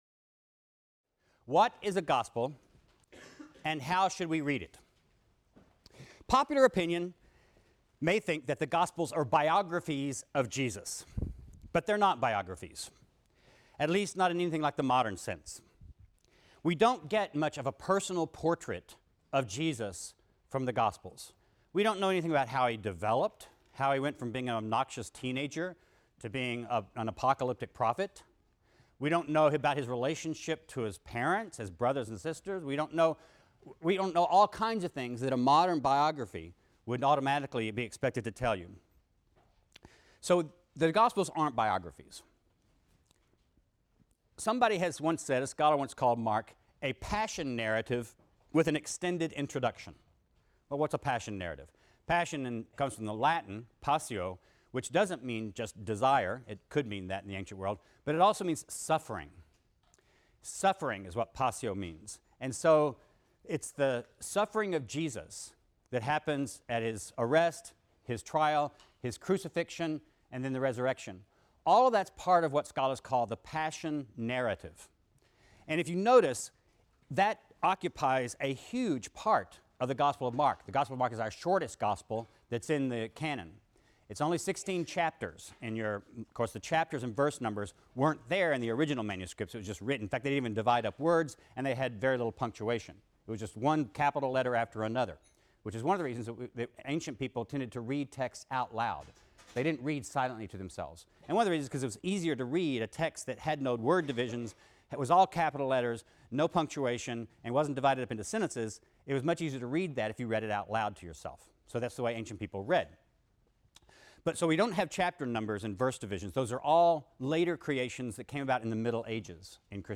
RLST 152 - Lecture 6 - The Gospel of Mark | Open Yale Courses